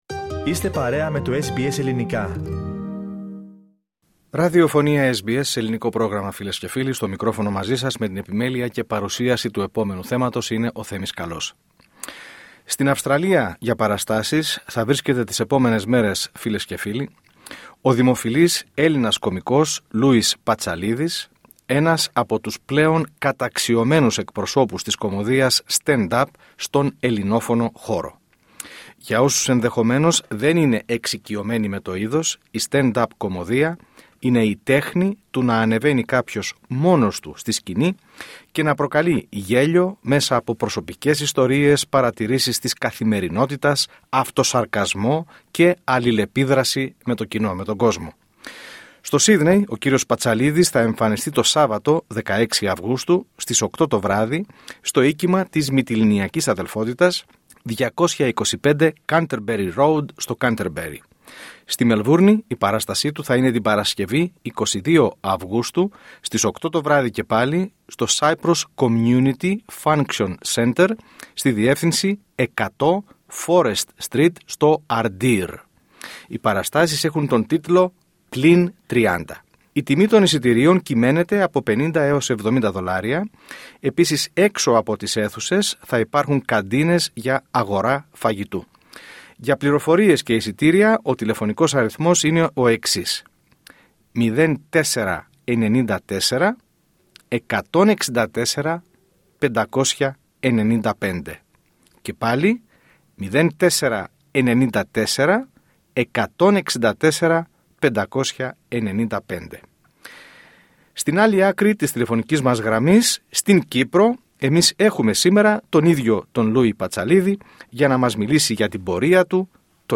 LISTEN TO Λούης (Λοϊζος) Πατσαλίδης κωμικός Stand Up 11'.22'' 11:40 Σε αποκλειστική συνέντευξη στο πρόγραμμά μας, SBS Greek, ο Λούης Πατσαλίδης μιλά με τον γνωστό αυθορμητισμό του για τη μαγεία της live επαφής με το κοινό, την έμπνευσή του από την καθημερινότητα, και τους λόγους που τον φέρνουν κοντά στο ομογενειακό κοινό της Αυστραλίας.